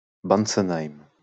Bantzenheim (French pronunciation: [bantsənaim]